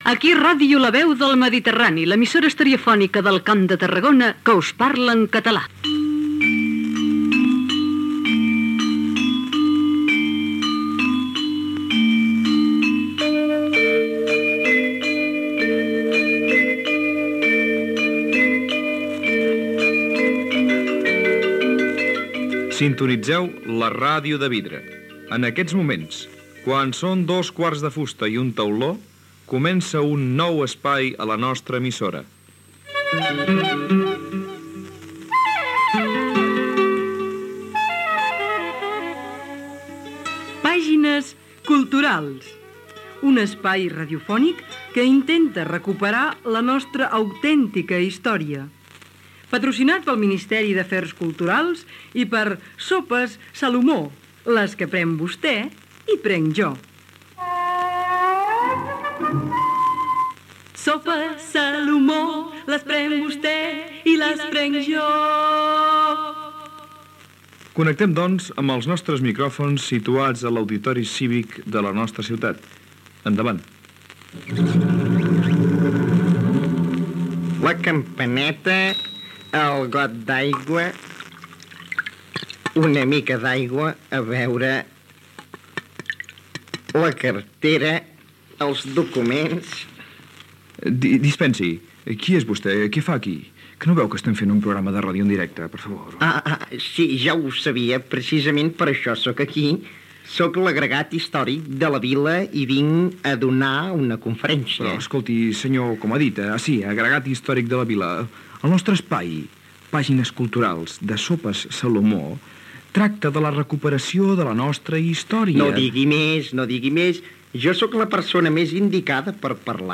Identificació de l'emissora, inici del programa "Pàgines culturals", publicitat de "Sopes Salomó" i diàleg sobre perquè les sabates caminen
Infantil-juvenil
FM